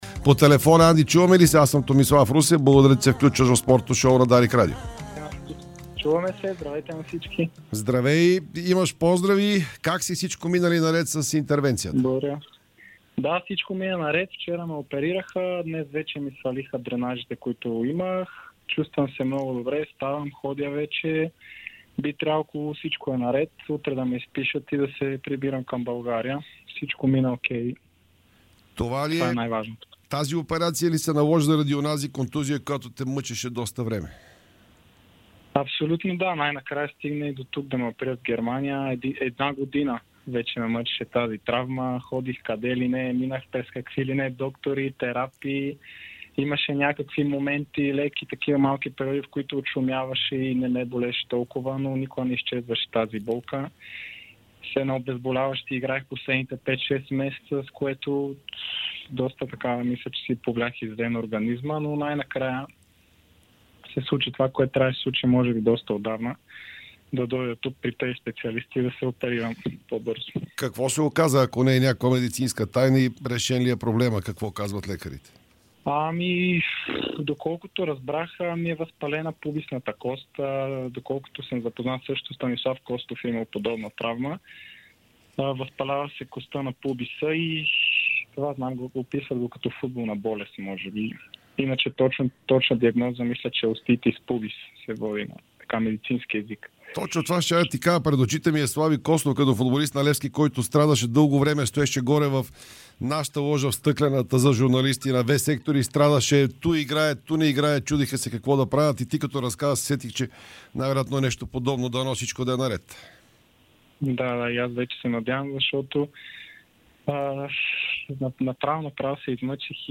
Андриан Краев даде специално интервю пред Дарик радио и dsport.